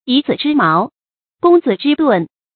yǐ zǐ zhī máo，gōng zǐ zhī dùn
以子之矛，攻子之盾发音